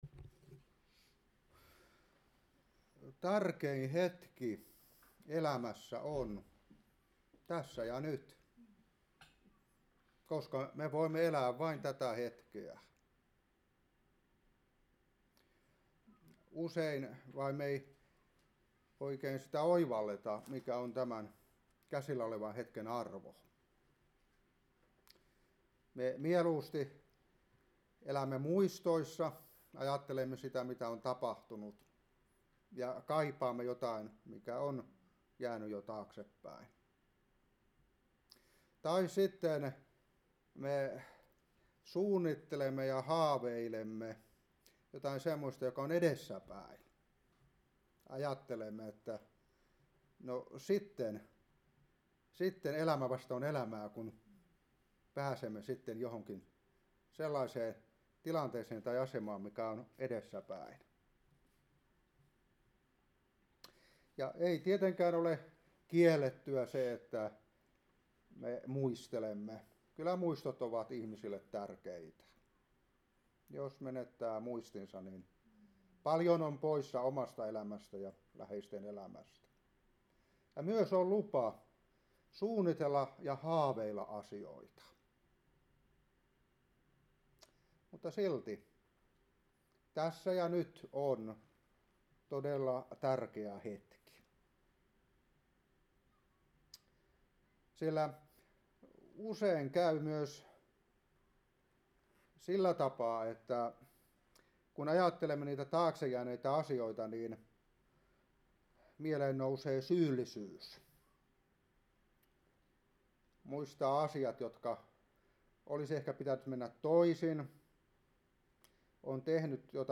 Raamattuopetus 2024-3.